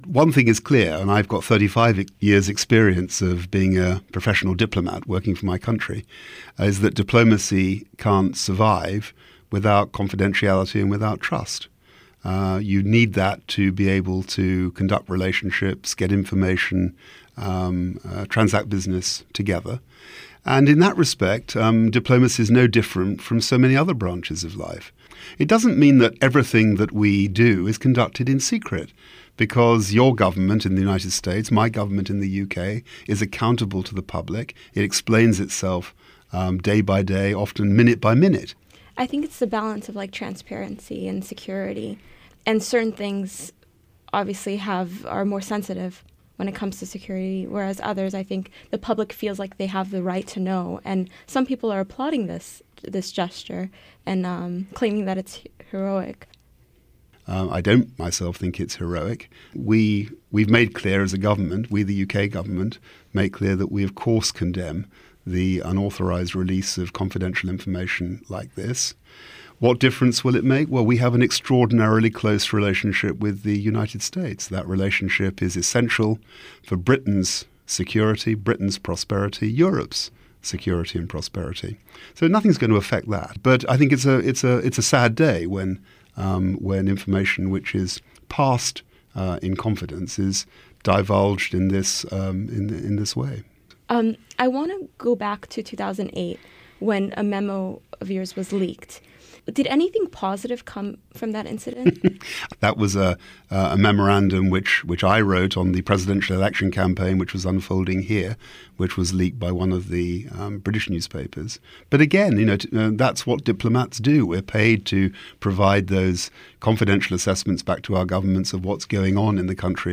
With 35 years of experience in foreign service, British Ambassador Sir Nigel Sheinwald speaks about the impact of Wikileaks.